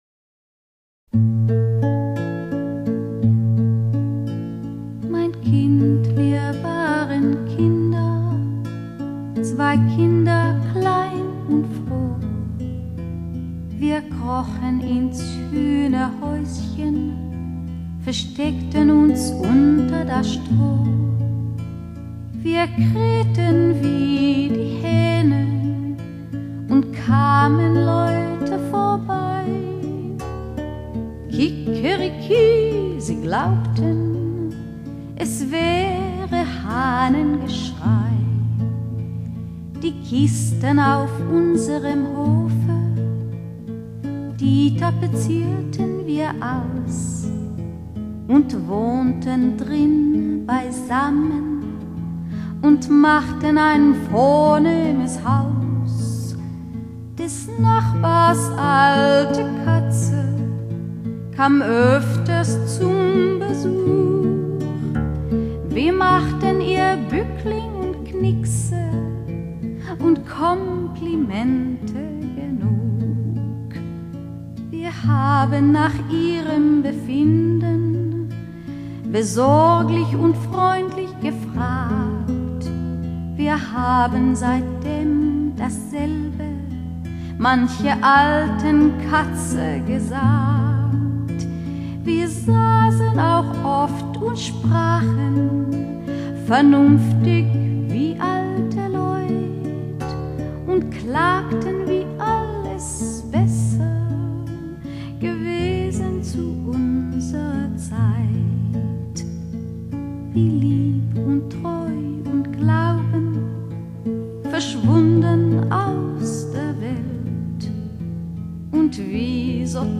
這是本世紀最親切感人的歌聲--Practical Hi Fi(英國)
噢！這是我聽過最美的人聲，她真摰的感情，使我感於五內--Hi Fi Exklusiv(德國)
樂曲旋律動聽，錄音夠發燒，感染力至高。